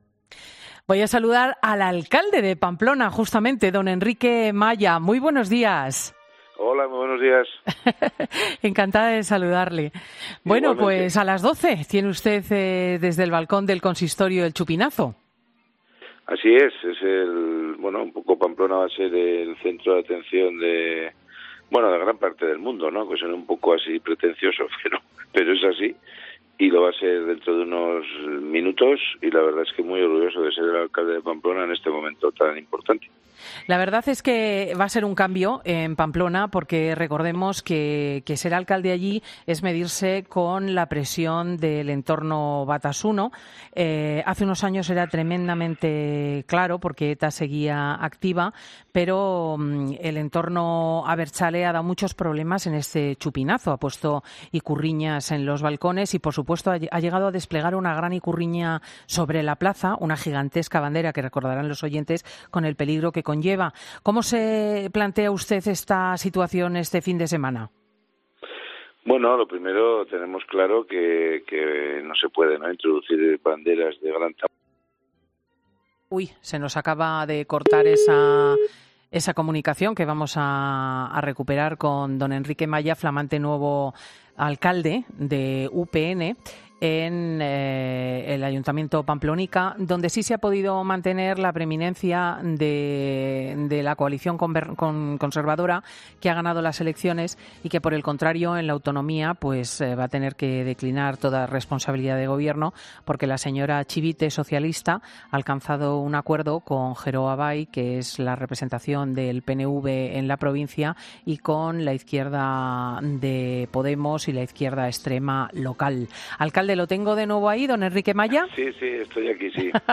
Enrique Maya, alcalde de Pamplona en 'Fin de Semana' con Cristina López Schlichting